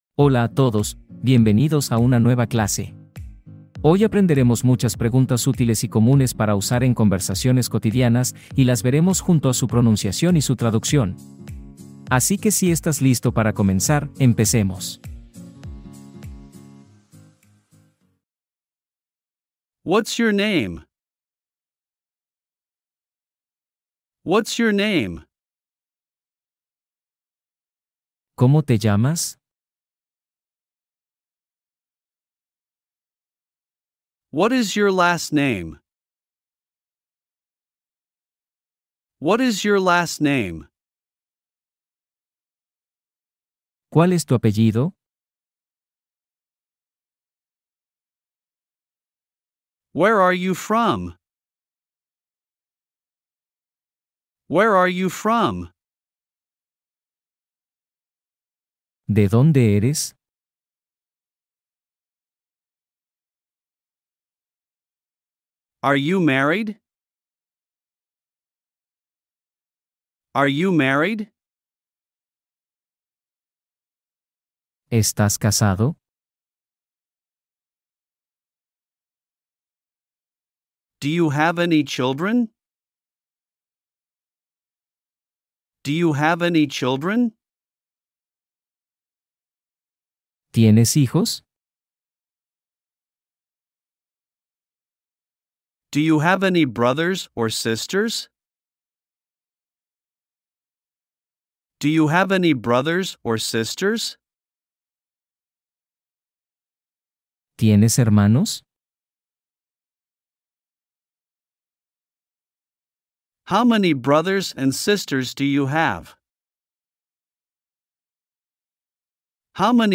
+50 preguntas en inglés para principiantes (explicadas fácil y lento)